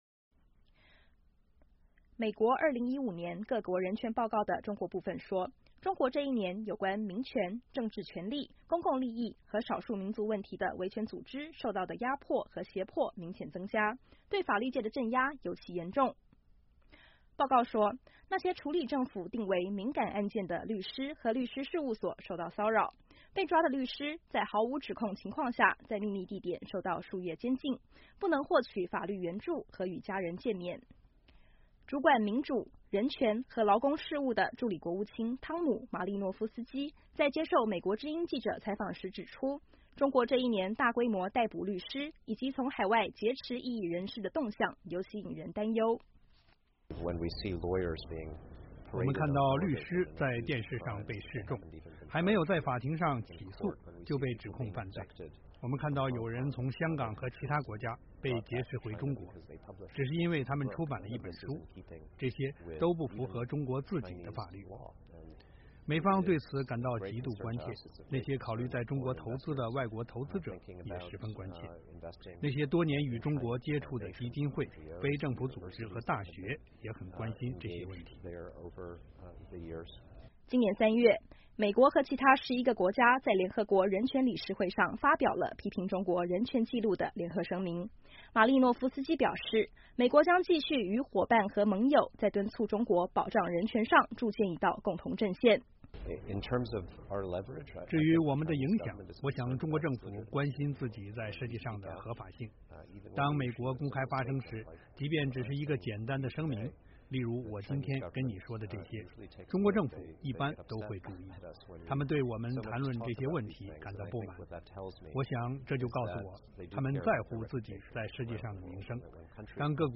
主管民主、人权和劳工事务的助理国务卿汤姆·马利诺夫斯基在接受美国之音记者采访时指出，中国这一年大规模逮捕律师以及从海外劫持异议人士的动向尤其引人担忧。